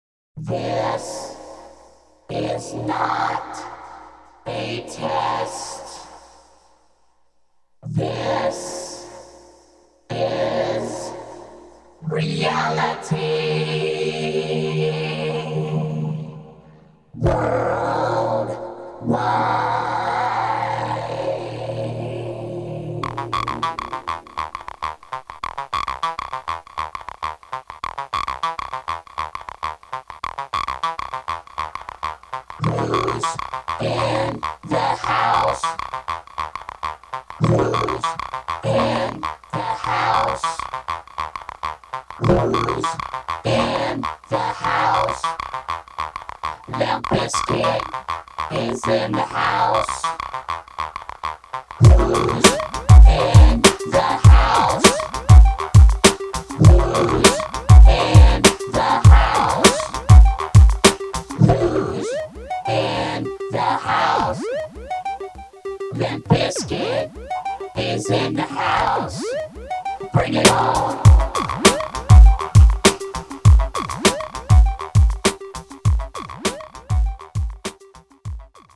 March 12, 2025 Publisher 01 Gospel 0
poet spoken words and reggae gospel artist.